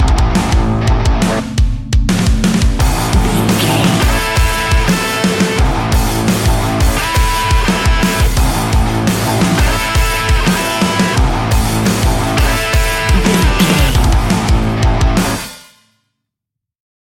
Ionian/Major
F♯
hard rock
heavy metal
instrumentals